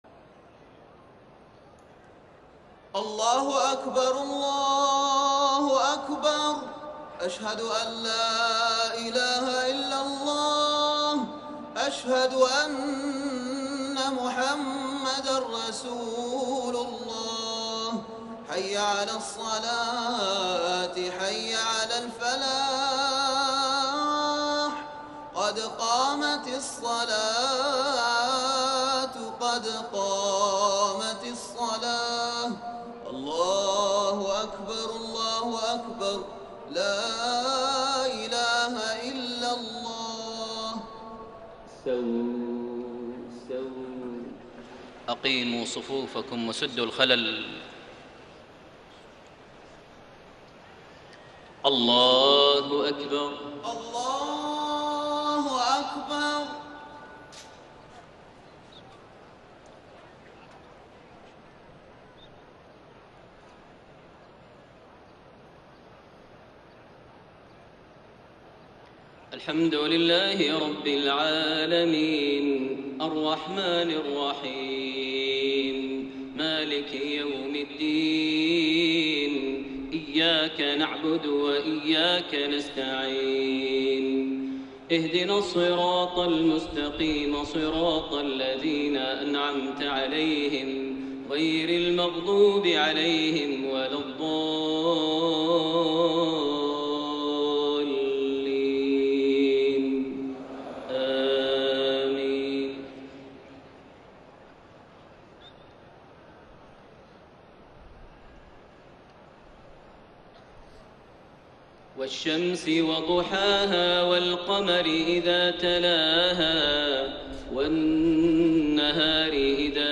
صلاة العشاء 18 رجب 1433هـ سورتي الشمس و الليل > 1433 هـ > الفروض - تلاوات ماهر المعيقلي